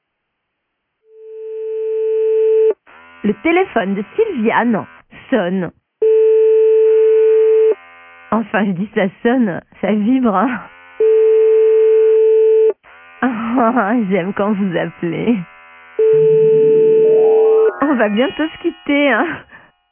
Blonde: Téléphone qui vibre.